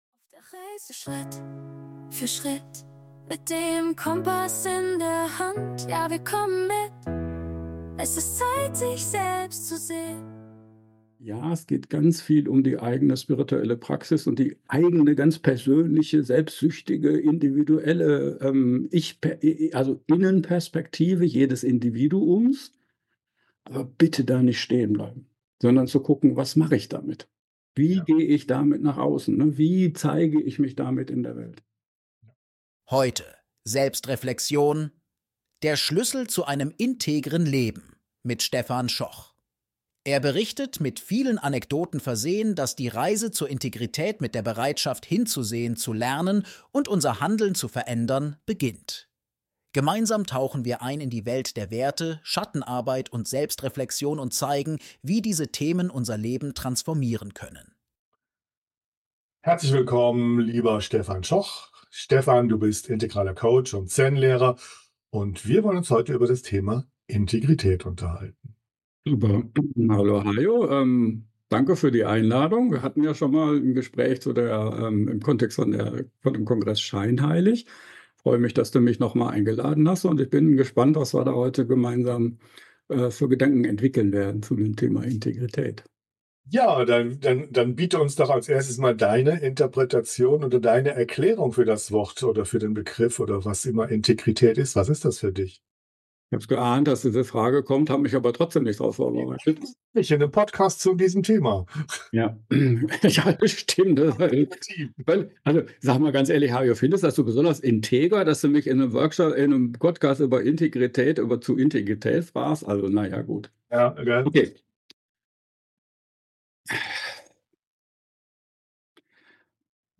********** PodcastInar Wenn dich das Interview angesprochen hat …